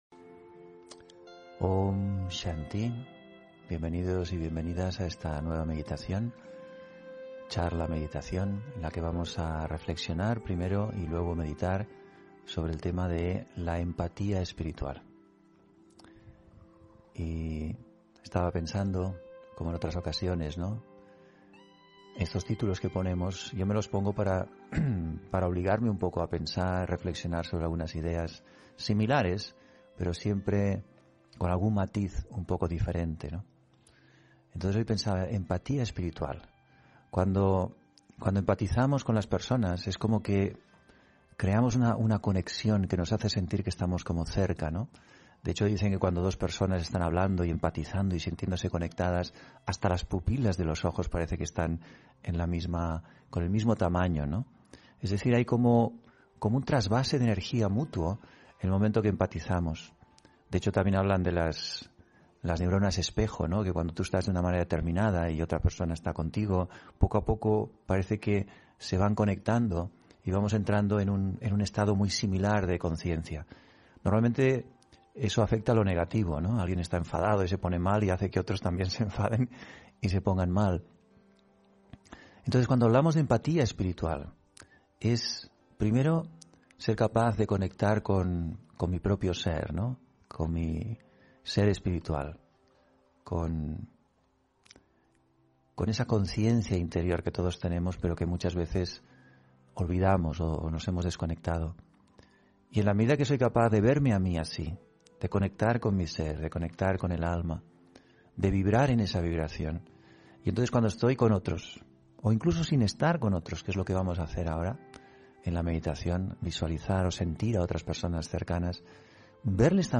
Meditación y conferencia: Empatía espiritual (19 Noviembre 2021)